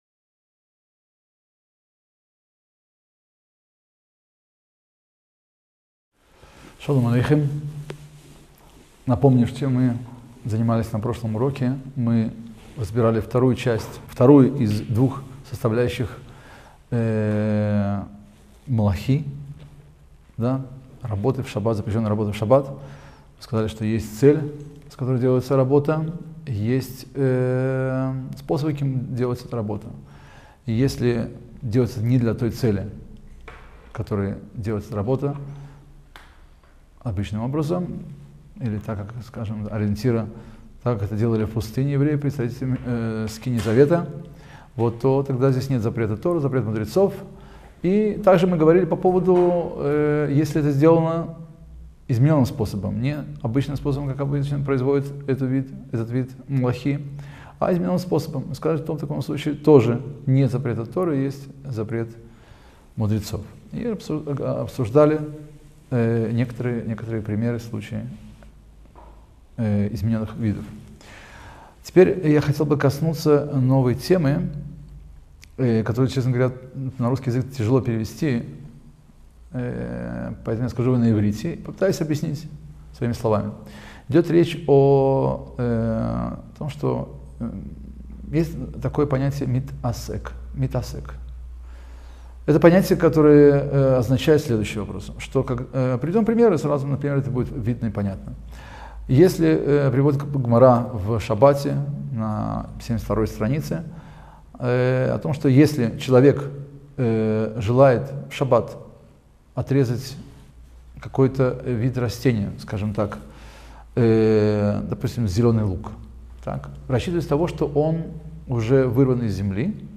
Краткие уроки по законам Субботы.